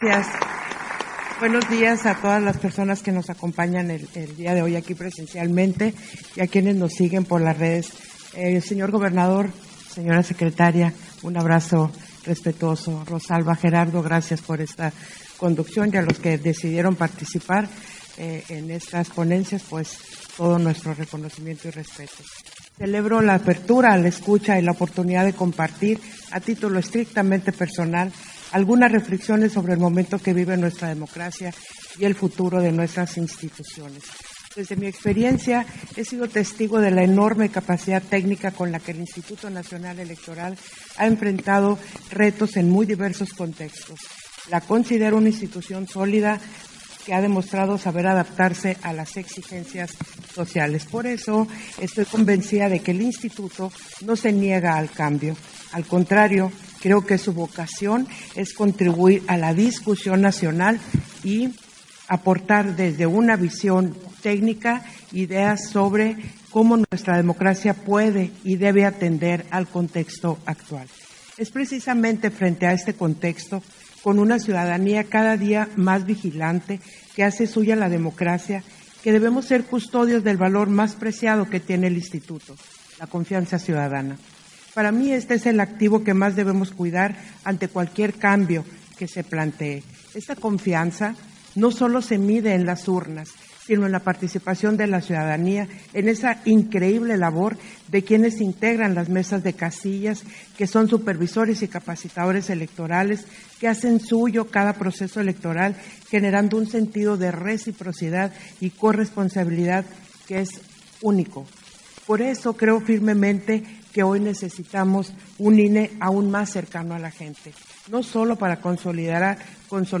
Participación de la Consejera Presidenta del INE, Guadalupe Taddei, en las audiencias públicas de la Comisión Presidencial para la Reforma Electoral